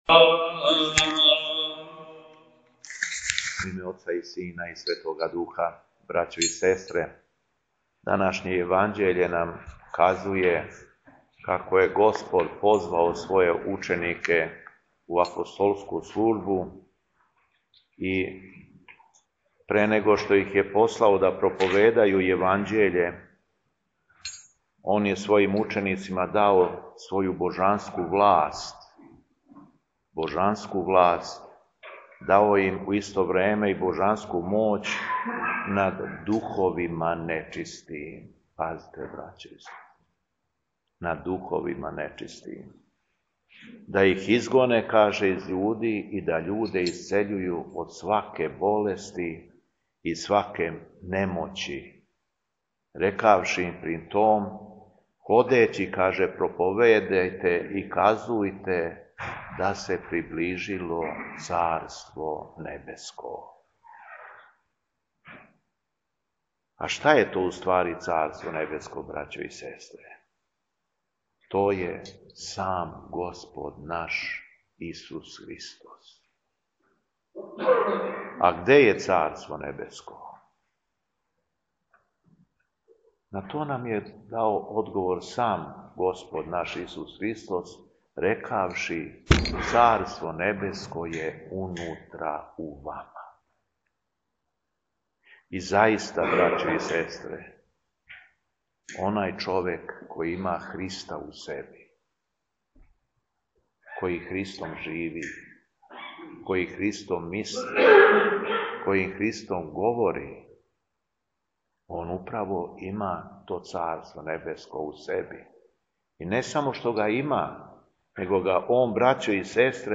У четвртак 13. фебруара 2025. године, Његово Високопресвештенство Митрополит шумадијски Г. Јован служио је Свету Литургију у Старој Цркви у Крагујевцу...
Беседа Његовог Високопреосвештенства Митрополита шумадијског г. Јована
Беседећи верном народу Митрополит Јован је рекао: